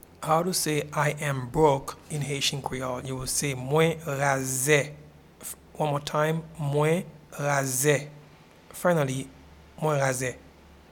Pronunciation and Transcript:
I-am-broke-in-Haitian-Creole-Mwen-raze.mp3